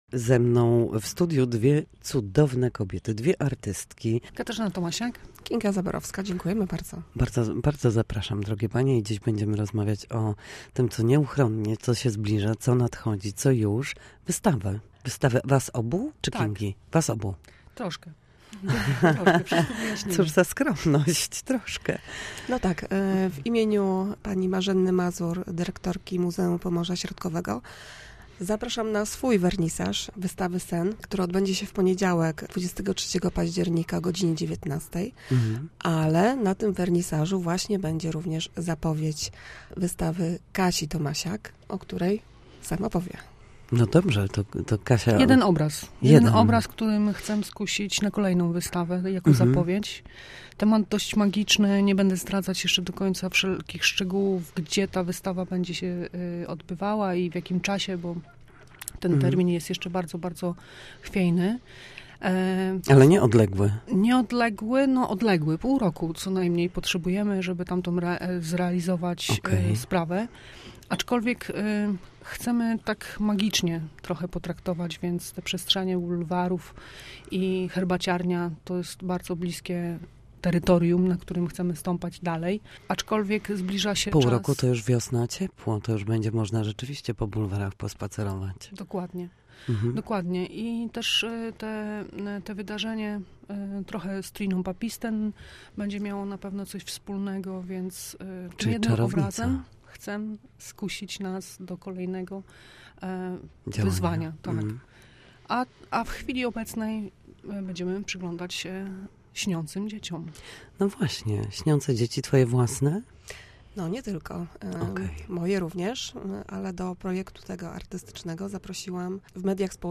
Posłuchaj rozmowy z artystkami: